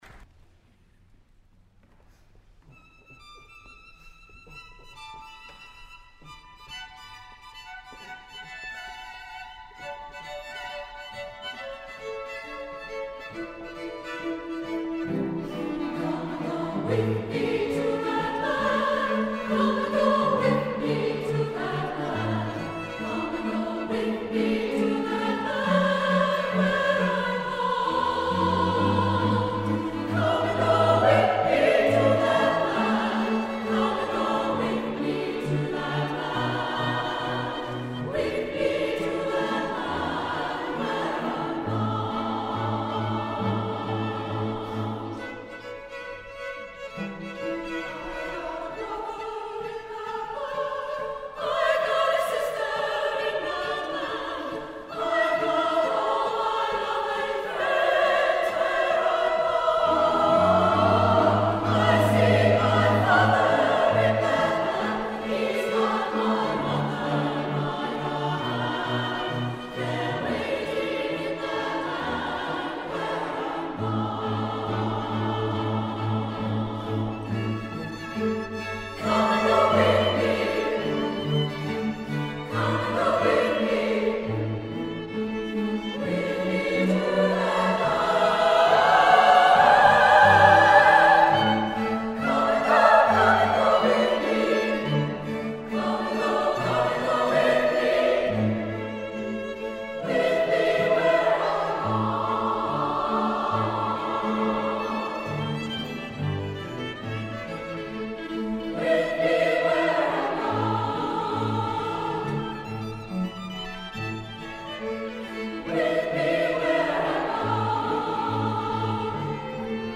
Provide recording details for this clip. (MP3 of SSA performance)